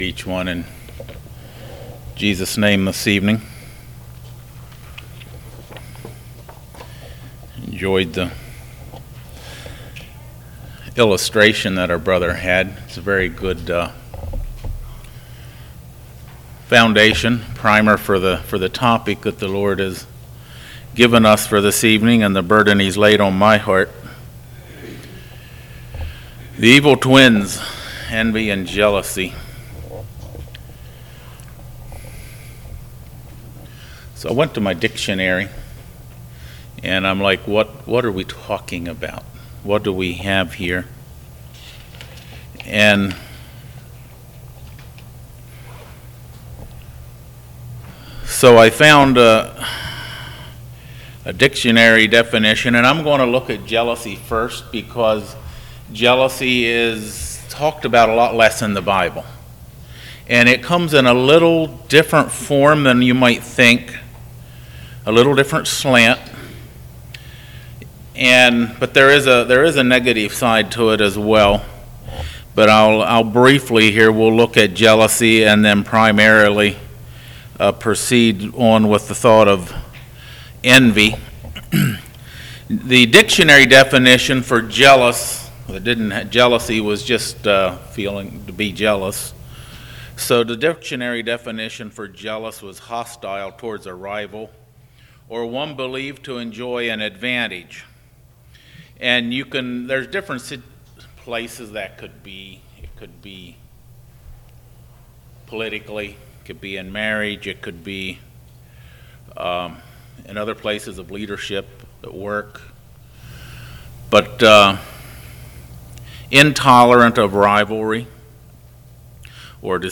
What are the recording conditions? Kirkwood | All Day Meetings 2024